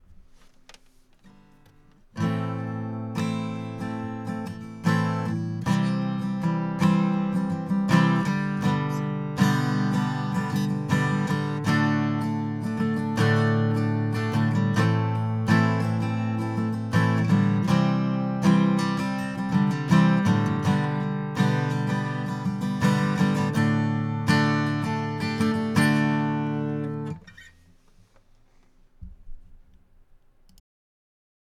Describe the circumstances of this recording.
Quickie with an SM57 and Tascam US144mkii (2.2kohm input impedance ). Micced about 8" off acoustic midway between soundhole and 12th fret. Unedited mp3 and waveform as recorded in Reaper.